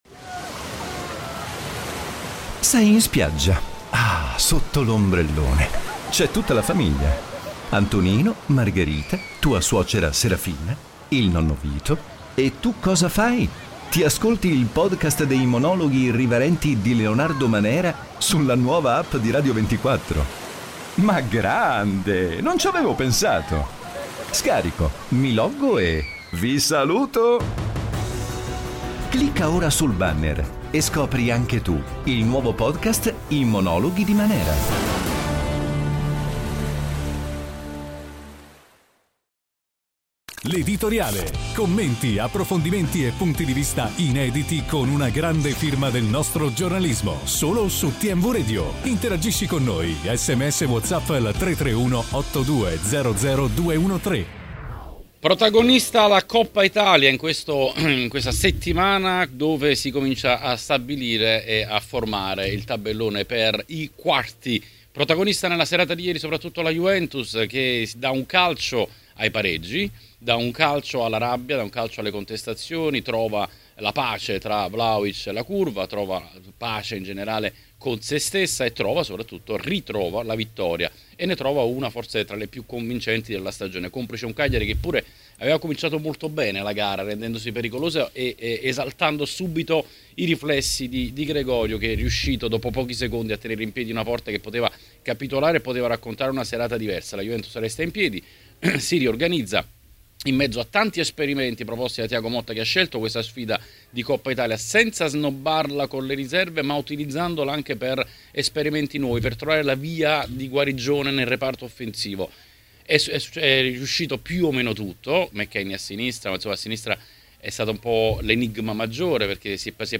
A Tmw Radio è l’ora dell’Editoriale.